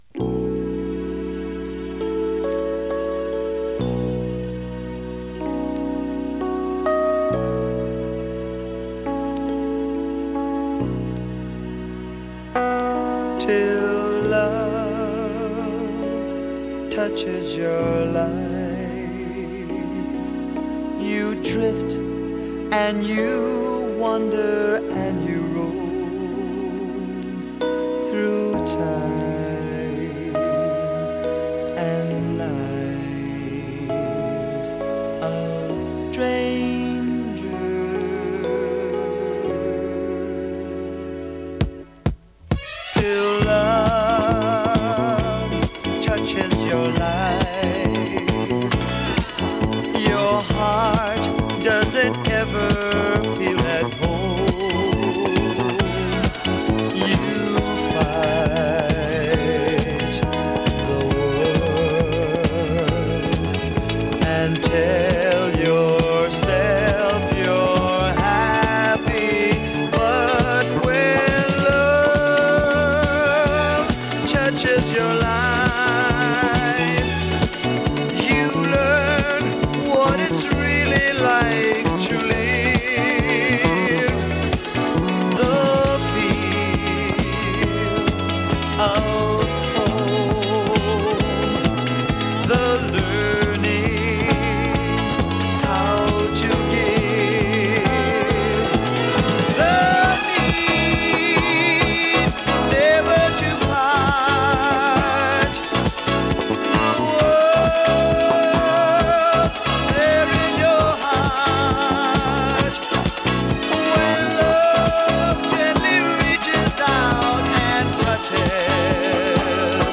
R&B、ソウル
音の薄い部分で時折軽いパチ・ノイズ。